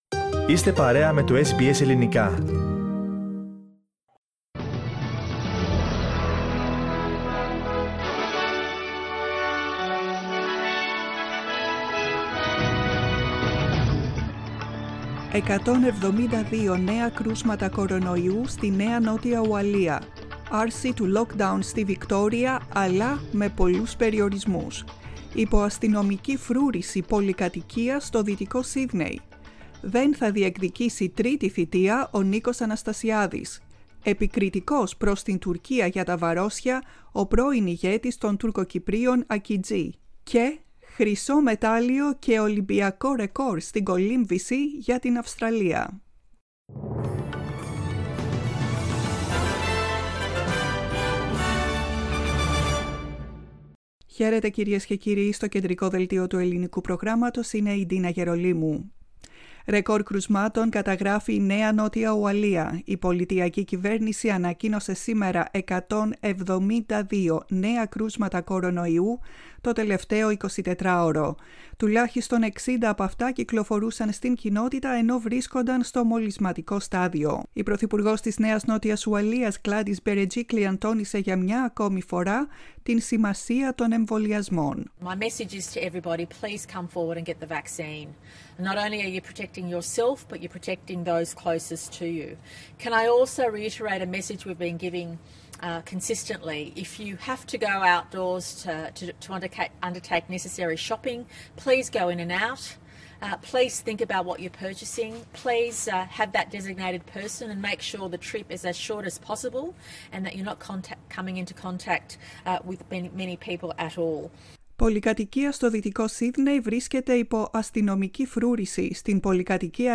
Δελτίο ειδήσεων στα Ελληνικά, 27.07.21
Το κεντρικό δελτίο ειδήσεων της ημέρας απ΄το Ελληνικό Πρόγραμμα.